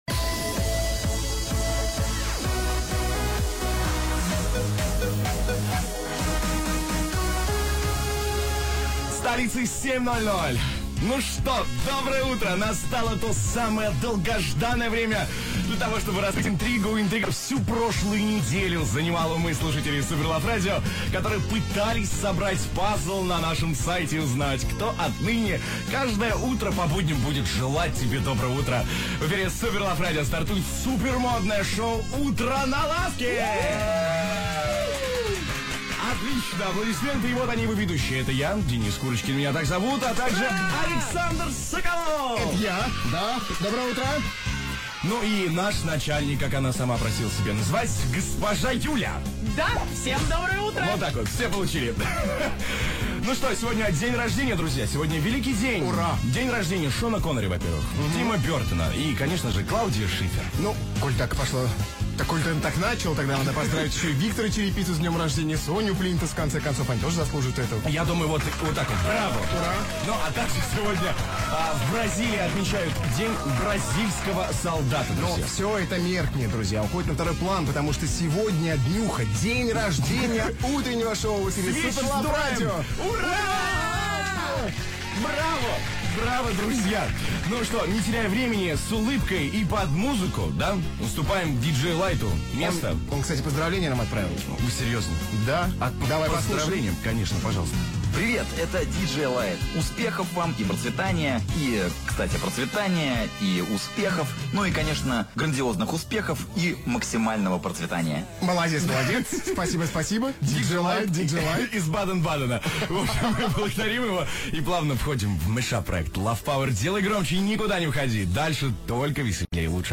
Формат: CHR
запись эфира